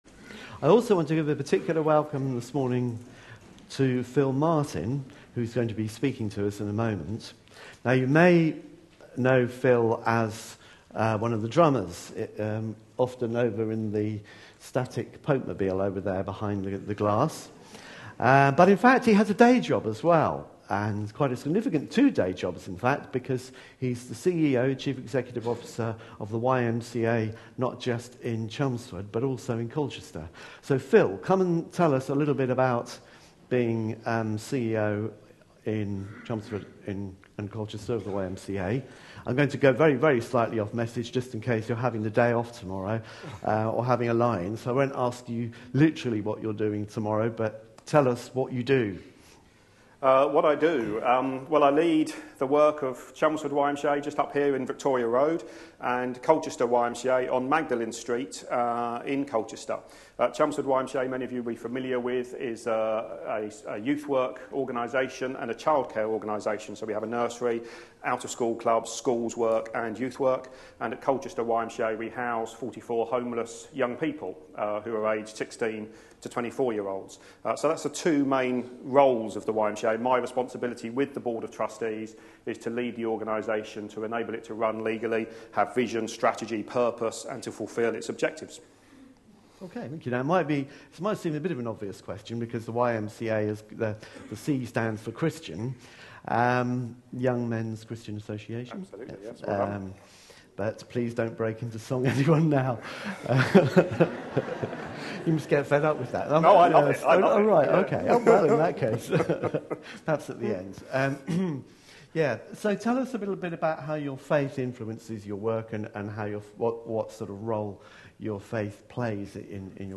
A sermon preached on 6th October, 2013, as part of our Objections to faith answered! series.